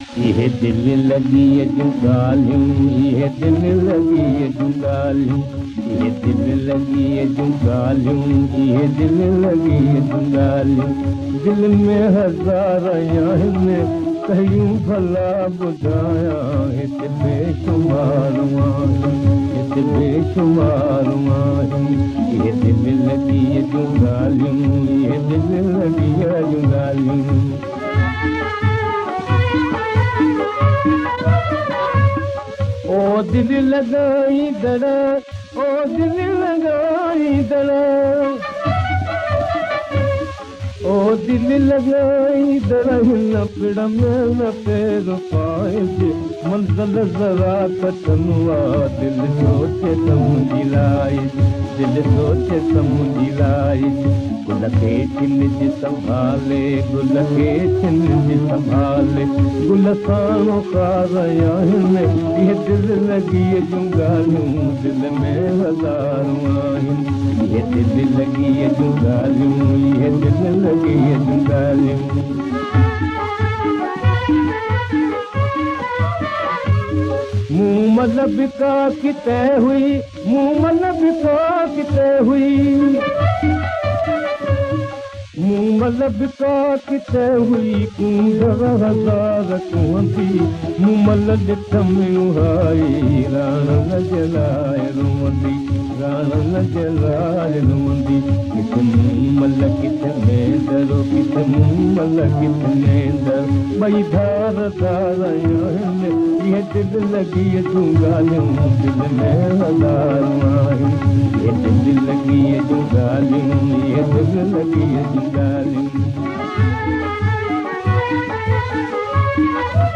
Converted from very old Gramophone records.